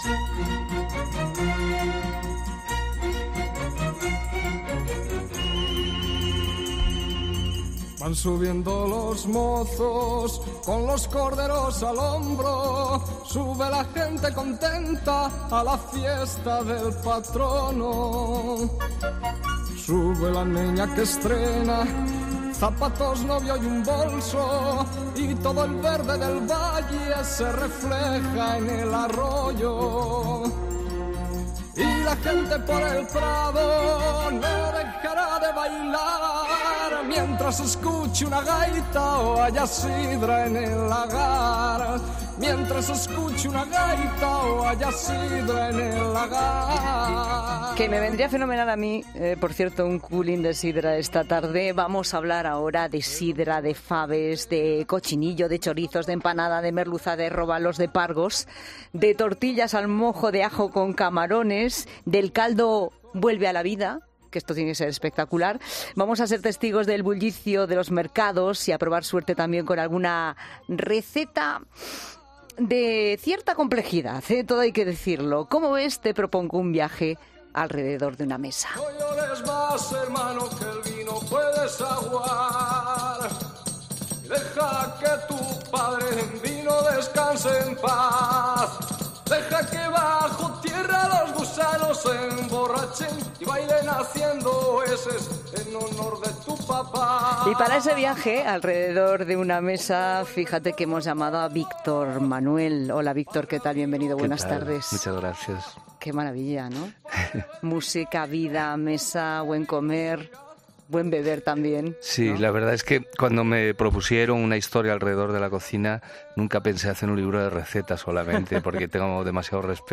Y para este viaje alrededor de una mesa, ha estado en La Tarde Víctor Manuel.